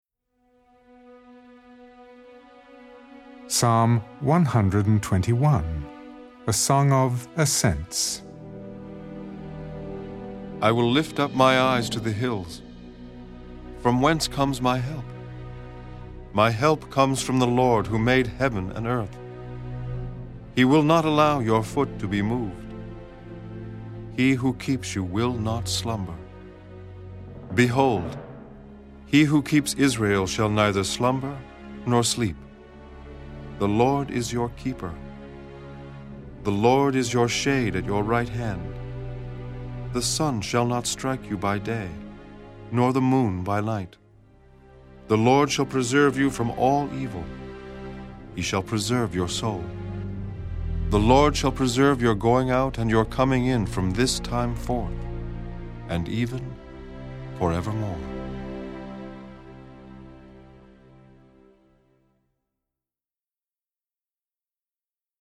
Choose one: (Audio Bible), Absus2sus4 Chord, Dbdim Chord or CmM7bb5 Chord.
(Audio Bible)